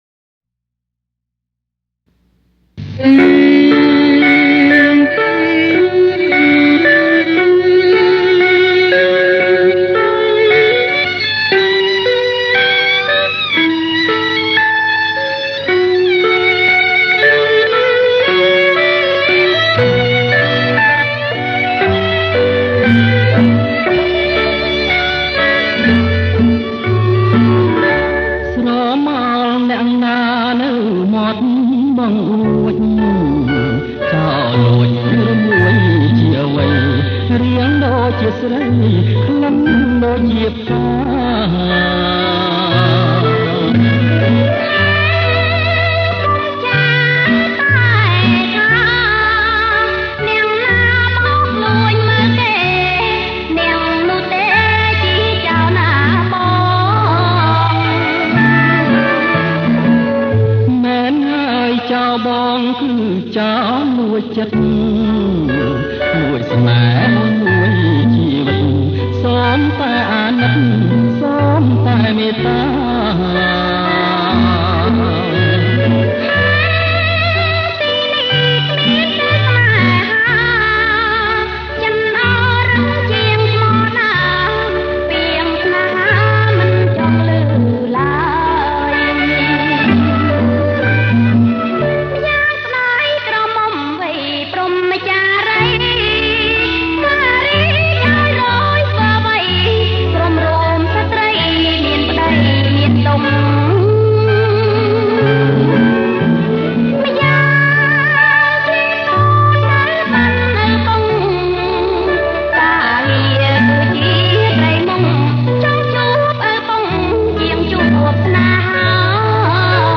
ប្រគំជាចង្វាក់ Bolero Lent